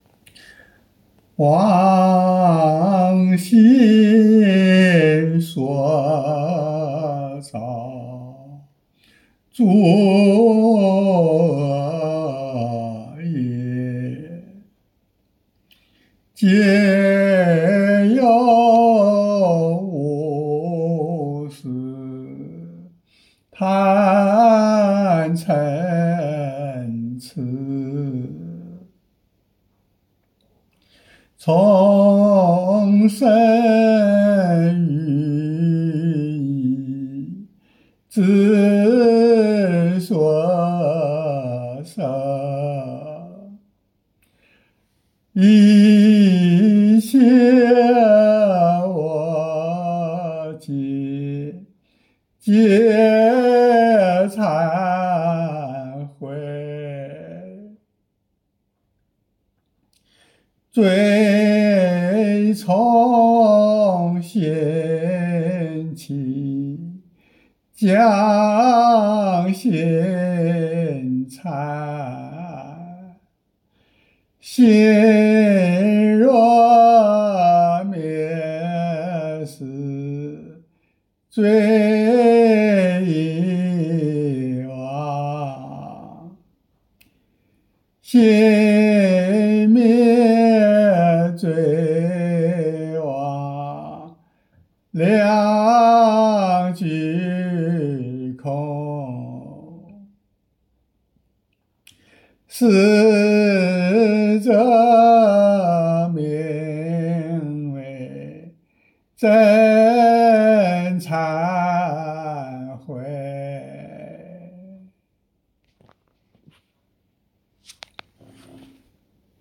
附录师父唱诵 ‘ 忏悔文 ’   忏悔偈 ’
其中的哭腔，是自己的悲心生起，不由自主地真心流露……真正的滴血流泪！